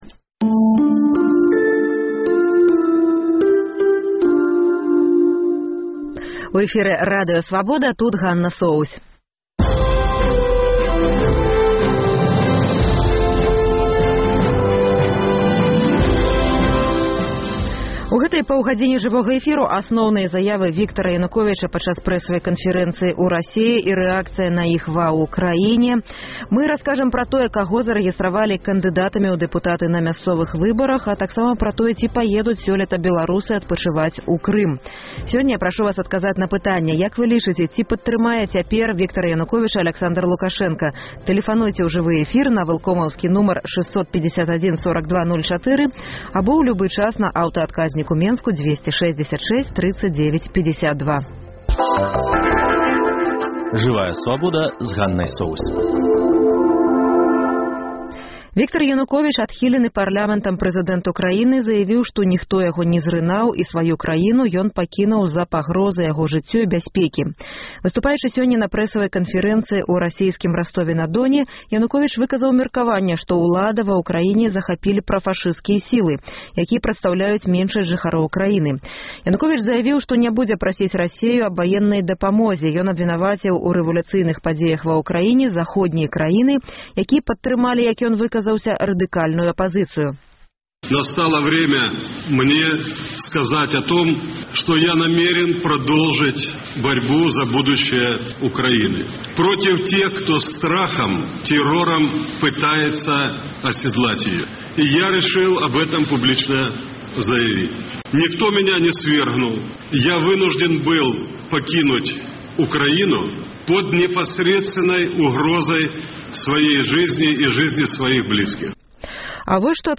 Тэлефануйце ў жывы эфір на вэлкомаўс